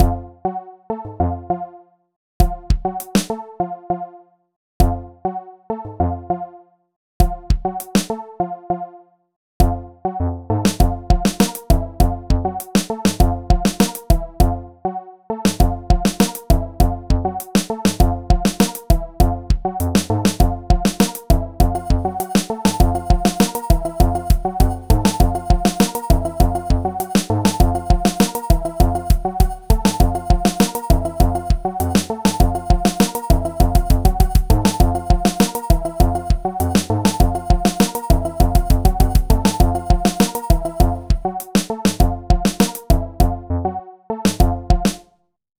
Bucle de Break Beat
Música electrónica
melodía
repetitivo
sintetizador